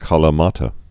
(kälə-mätə, kălə-)